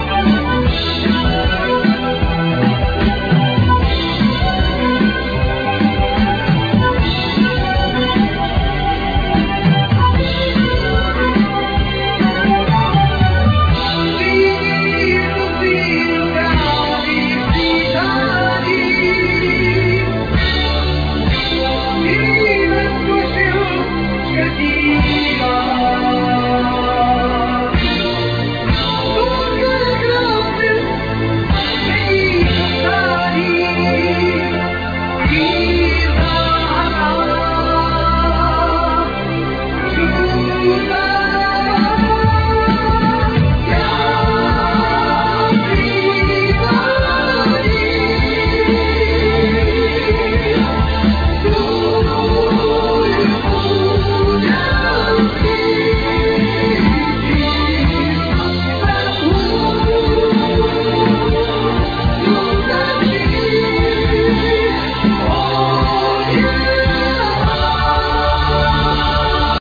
Micromoog, ARP Omni, Clavinet,Fender piano,Piano,Vocal
Ac. guitar,Micromoog, Ckavinet, Cello, Vocal
Micromoog, ARP Omni, Fender piano, Violin, Vocal
El. guitar, Vocal
Drums, Percussion, Micromoog, Vocal